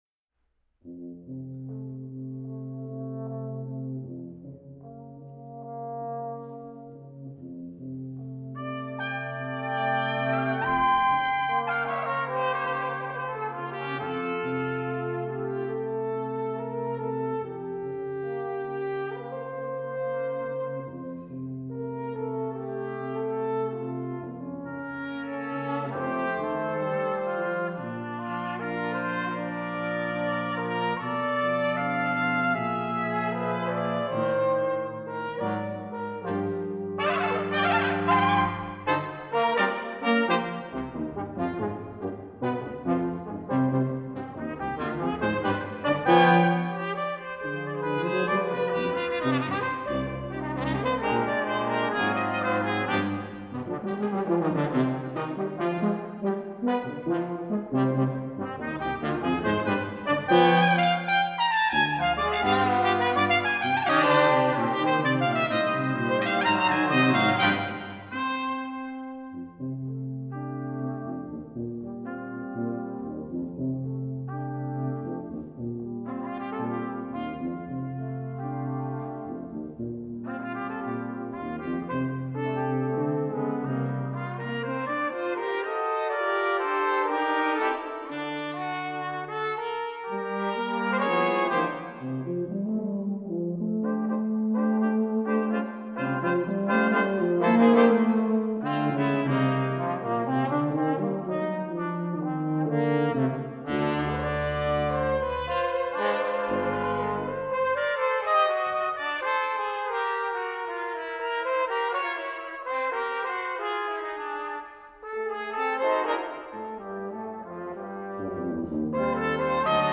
I just received the clips from Triton's performance at the first BUTI Wind Ensemble concert in Ozawa Hall at Tanglewood last July. Couple movements from Paquito D'Rivera's Four Pieces for brass quintet.